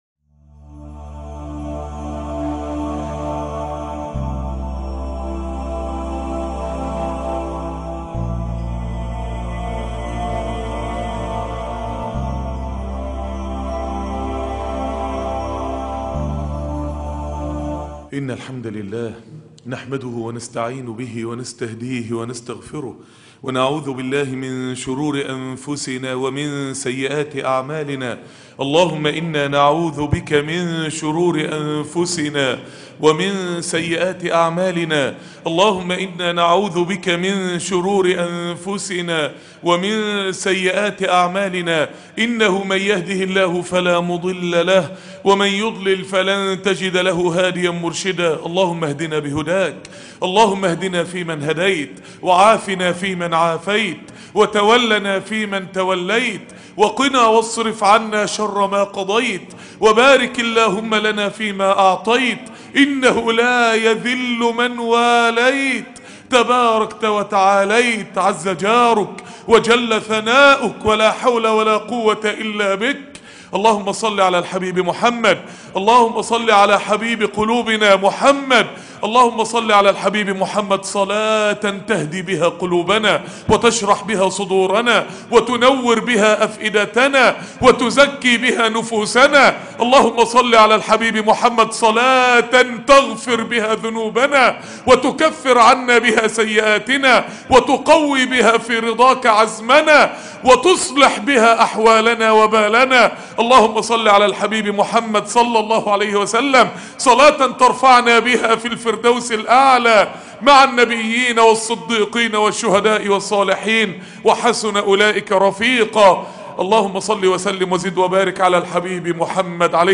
أصلها ثابت و فرعها في السماء ( خطب جمعة